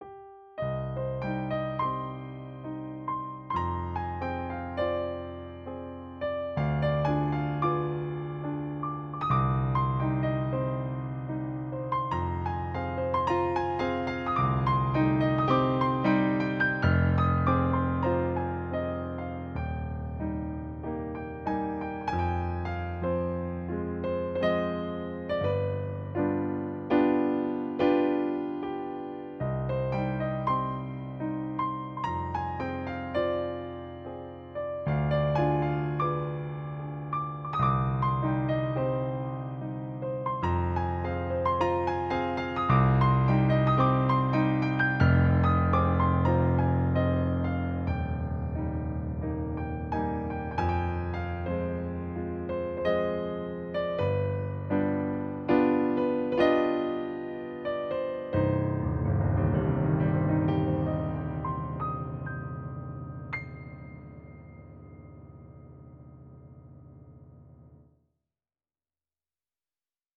Mein Haupt-Hobby, Klavierkompositionen: